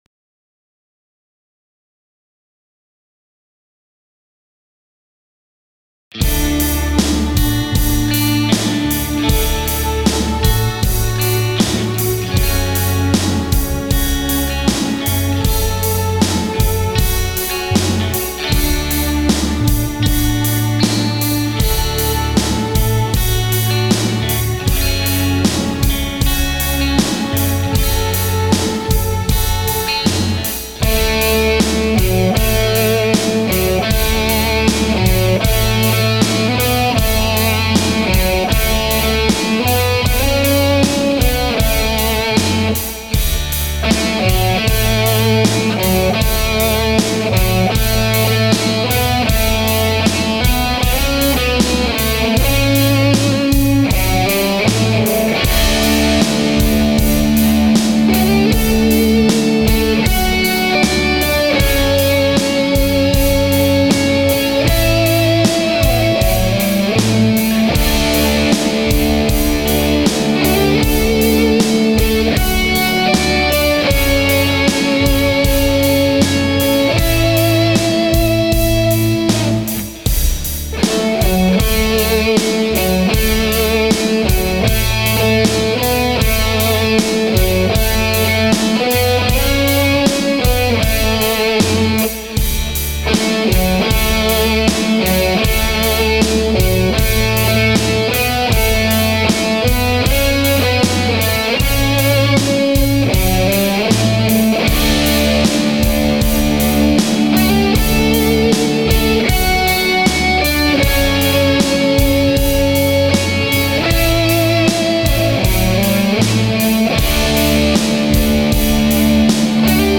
Mixcraft 6 is doing the string sections